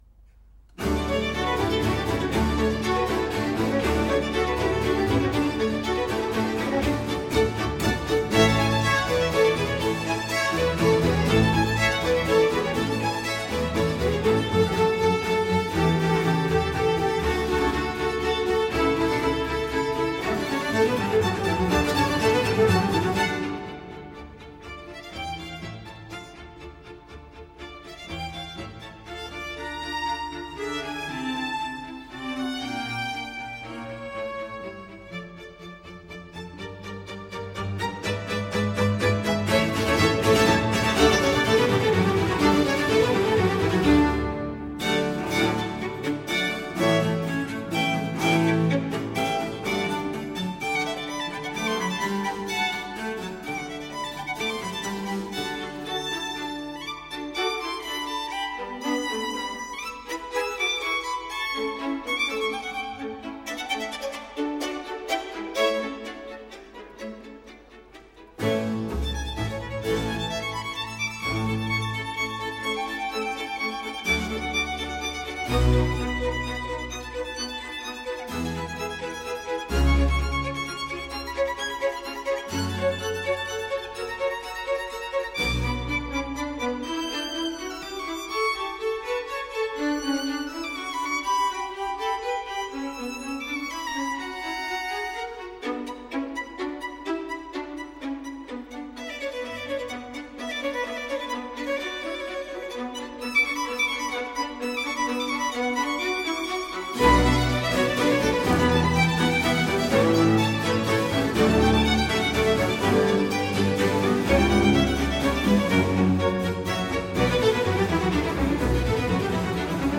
Violin concerto
Concerto for violin, strings, continuo in D